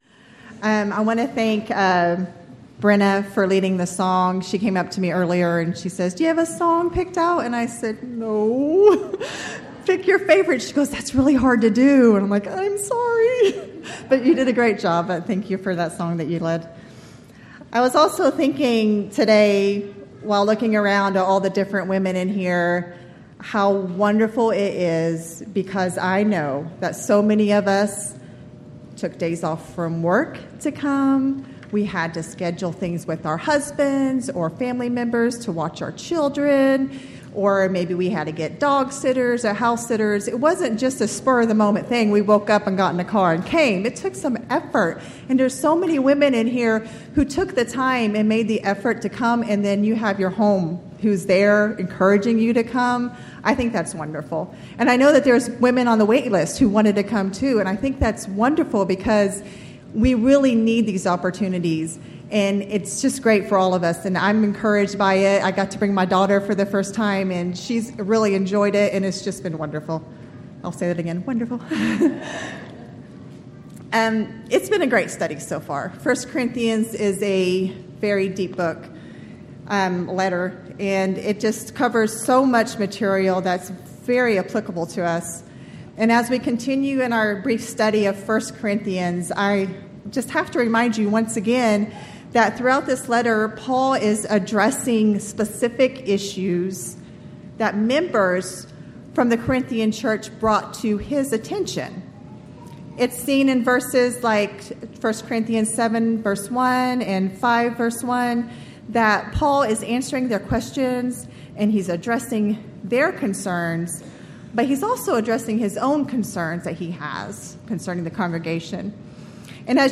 Event: 13th Annual Texas Ladies in Christ Retreat
Ladies Sessions